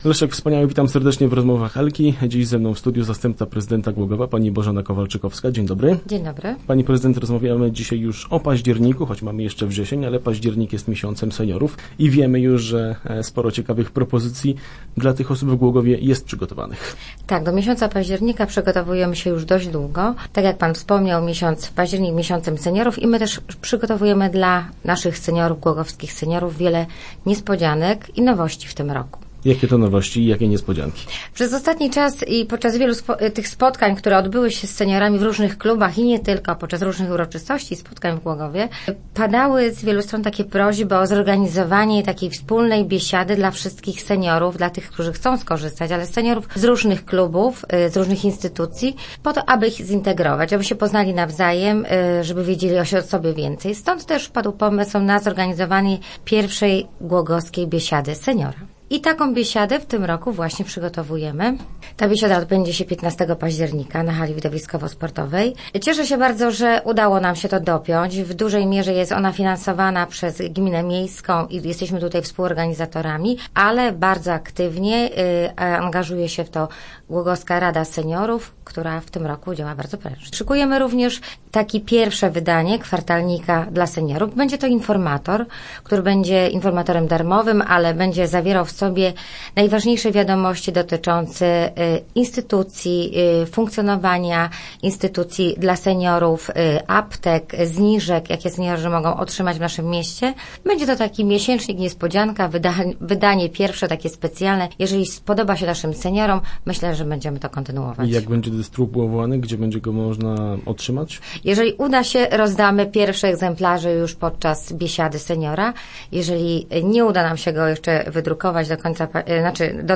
zastępca prezydenta miasta Bożena Kowalczykowska